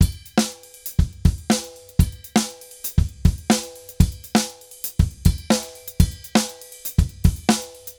Drums_Candombe 120_3.wav